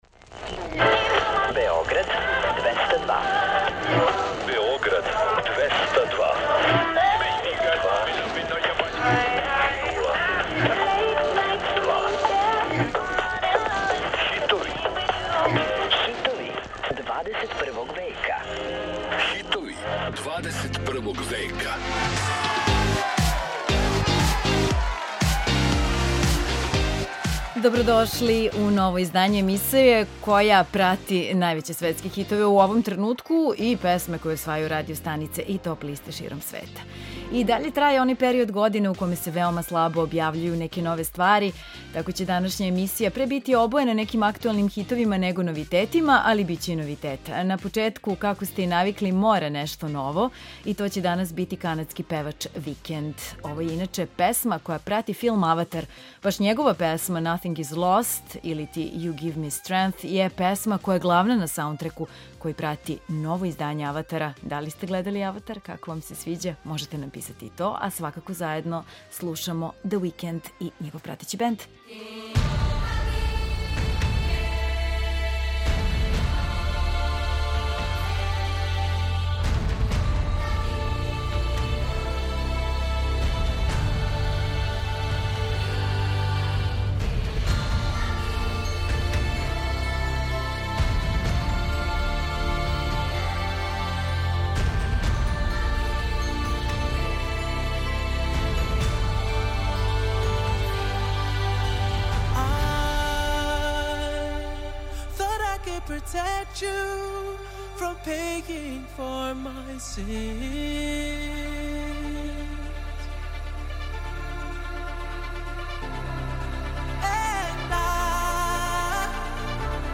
Čućete pesme koje se nalaze na vrhovima svetskih top lista.